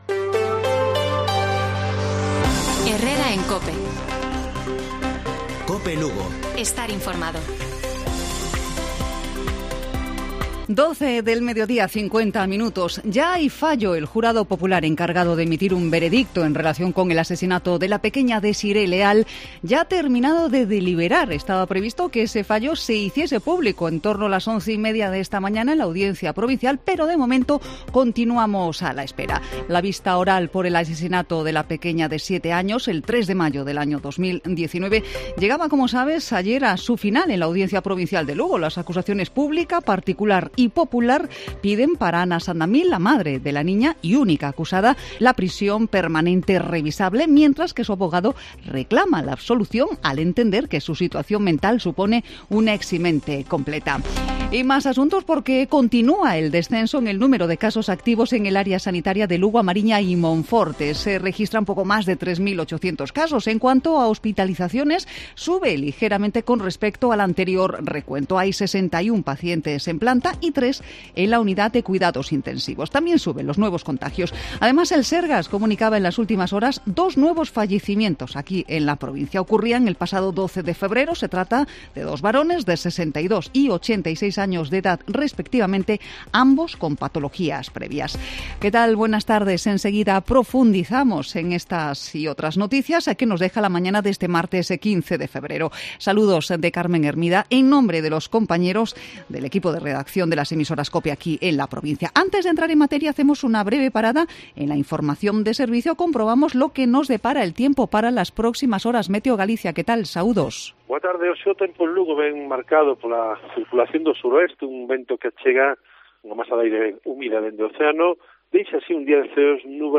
Informativo Provincial Cope Lugo.